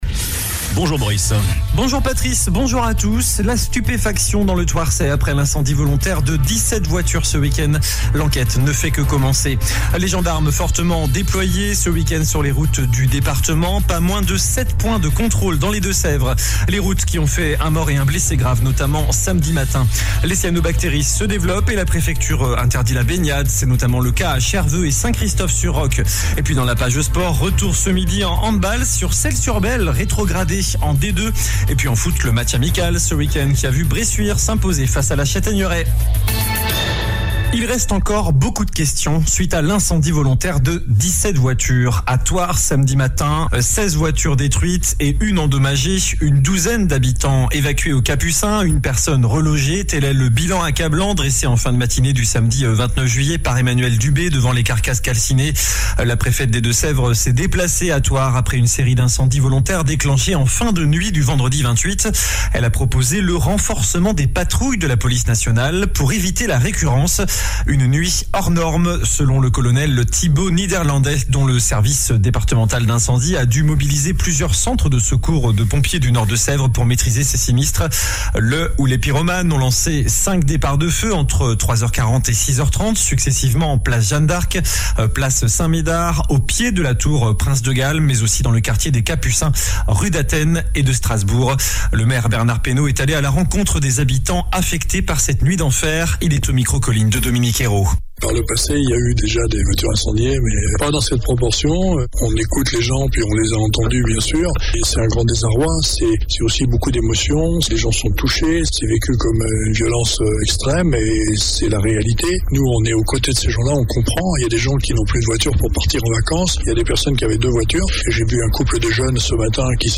JOURNAL DU LUNDI 31 JUILLET ( MIDI )